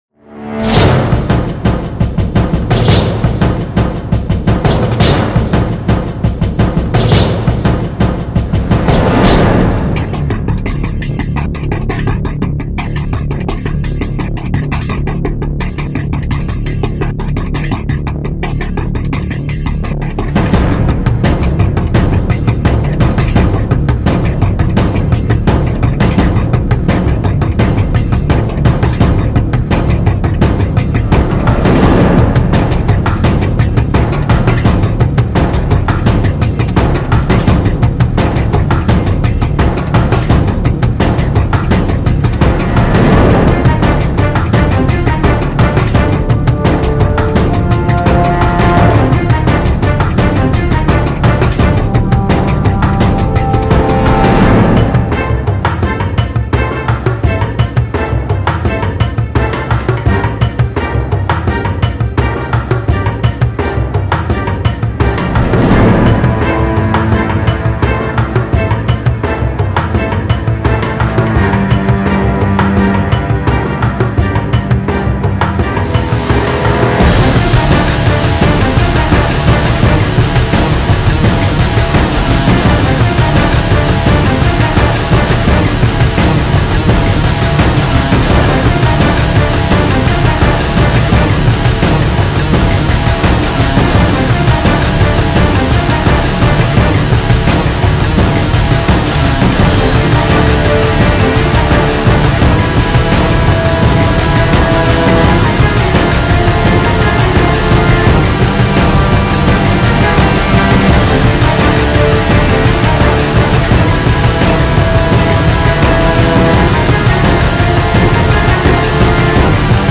• Musica epica medieval para crear tension e intriga
Instrumental, Rock
Música instrumental épica para crear tensión